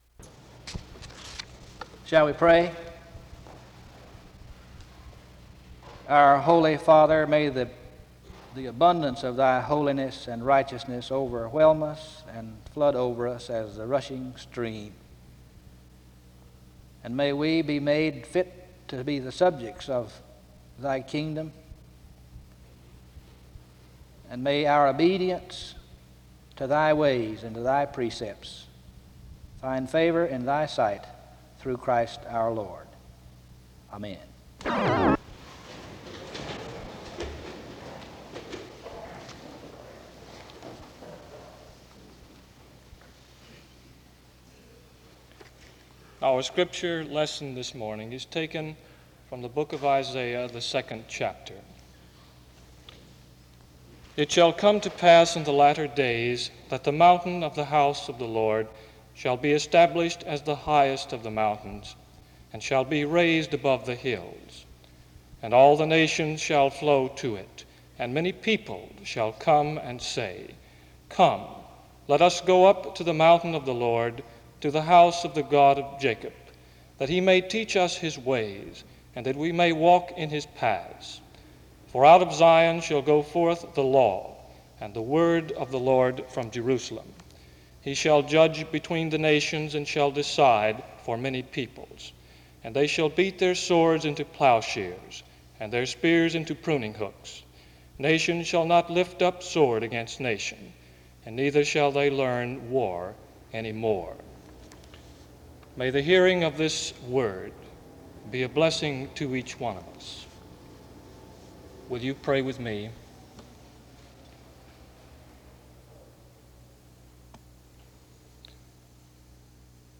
The service starts with a prayer from 0:00-0:34. Isaiah 2 is read from 0:46-1:50. A prayer is offered from 1:51-3:42.
His message was centered on sharing biblical imagery to a modern world about the gospel despite the possibilities of it being outdated. The service closes with music from 48:36-48:52.